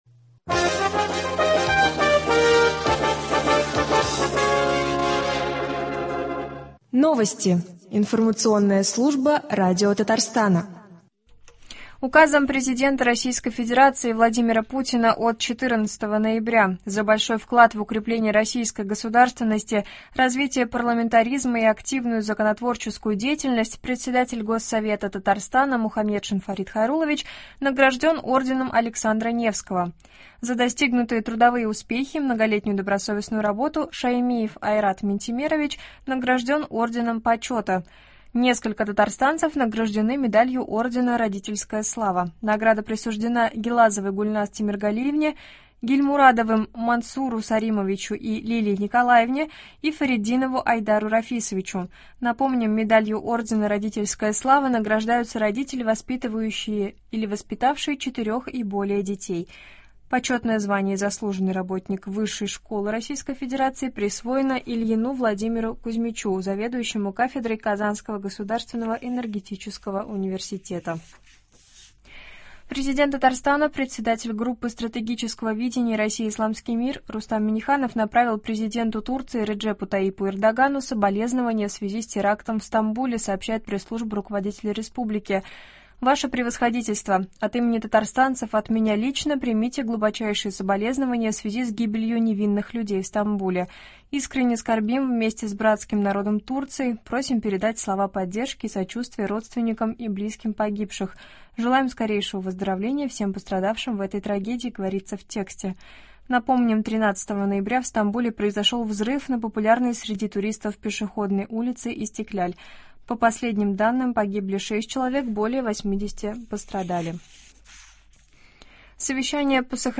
Новости (14.11.22)